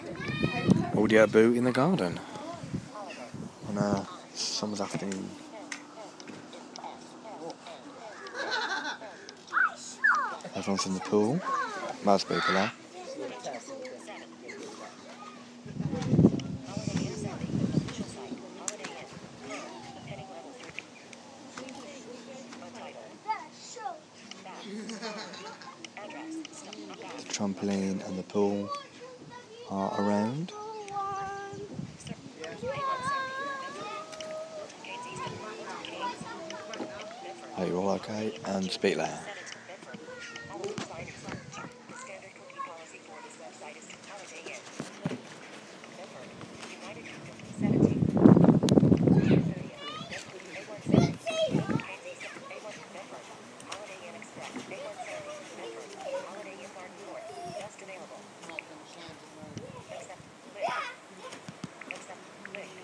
in garden